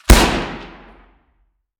weap_juliet_launch_atmo_int_03.ogg